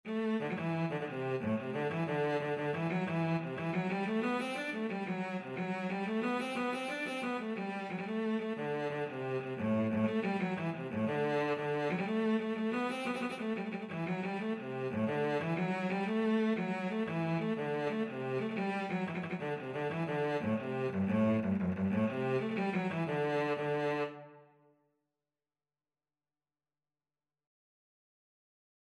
Traditional Turlough O Carolan Miss Noble Cello version
G major (Sounding Pitch) (View more G major Music for Cello )
6/8 (View more 6/8 Music)
F#3-D5
Cello  (View more Easy Cello Music)
Traditional (View more Traditional Cello Music)